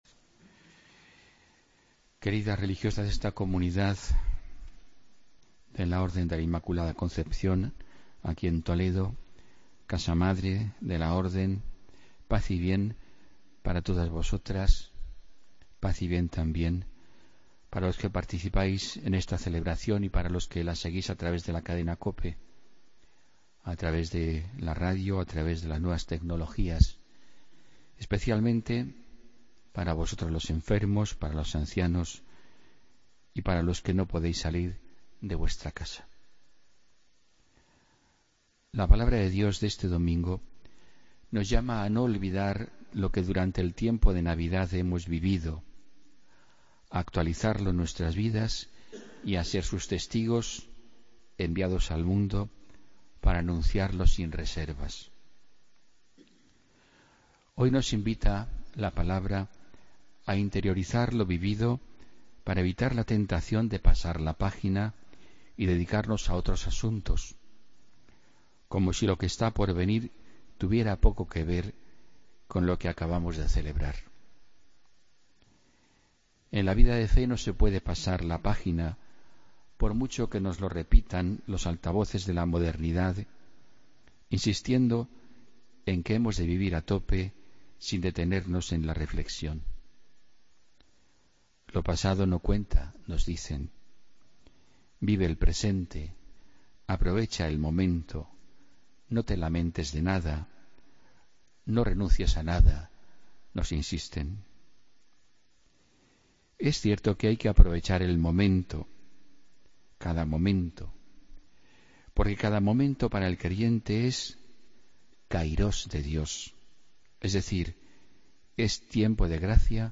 Homilía del domingo 15 de enero de 2017